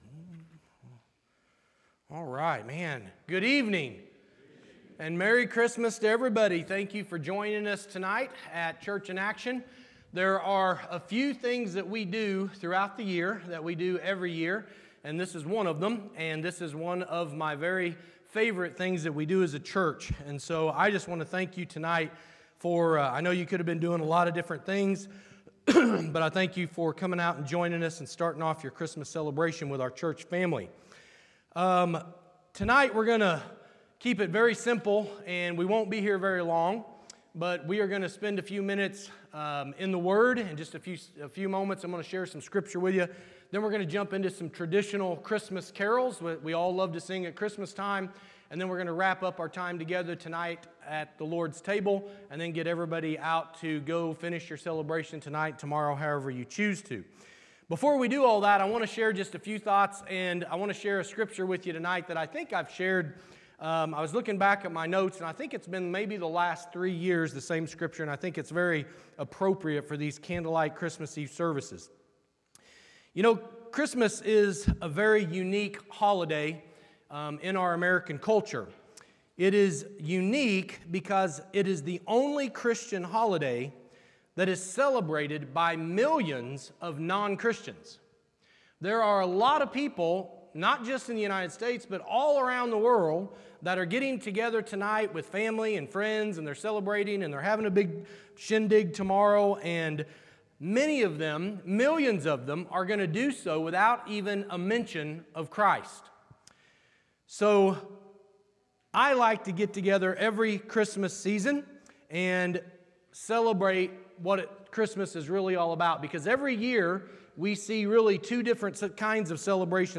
Christmas Eve Service 2025